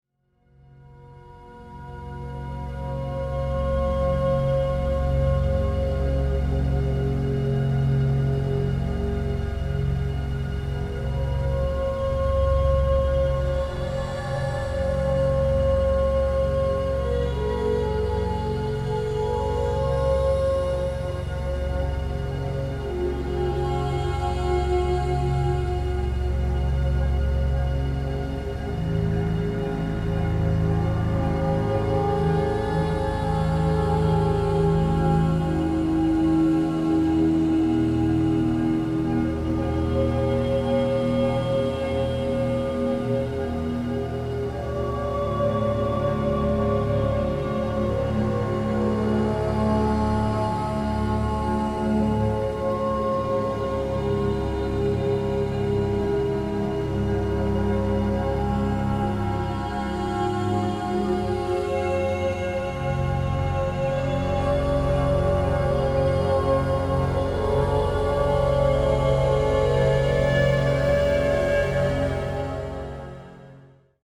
FREE mp3 sample featuring part of this soundscape!